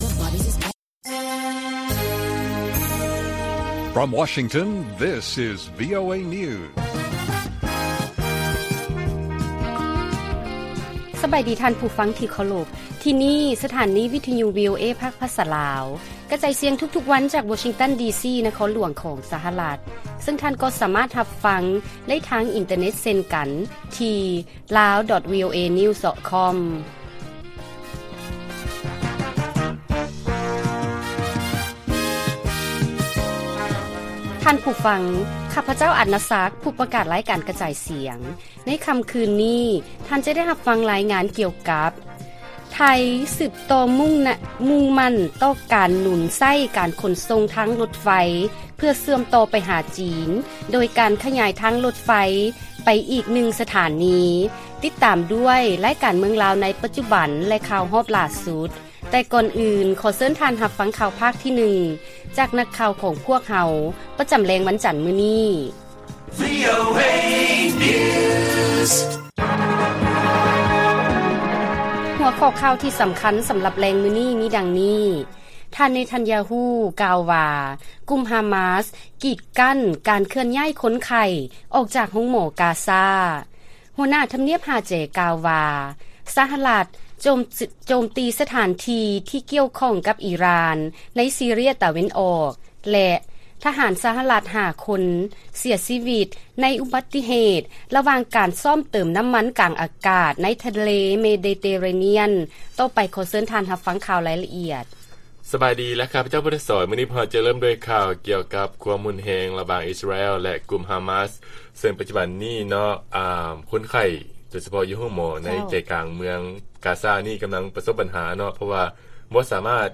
ວີໂອເອພາກພາສາລາວ ກະຈາຍສຽງທຸກໆວັນ ເປັນເວລາ 30 ນາທີ. ຫົວຂໍ້ຂ່າວທີ່ສຳຄັນສຳລັບມື້ນີ້ມີດັ່ງນີ້: ທ່ານ ເນຕັນຢາຮູ ກ່າວວ່າກຸ່ມຮາມາສ ກີດກັ້ນການເຄື່ອນຍ້າຍຄົນໄຂ້ອອກຈາກໂຮງໝໍ ກາຊາ, ຫົວໜ້າທຳນຽບຫ້າແຈ ກ່າວວ່າ ສະຫະລັດ ໂຈມຕີສະຖານທີ່ ທີ່ກ່ຽວຂ້ອງກັບອີຣ່ານ ໃນຊີເຣຍຕາເວັນອອກ.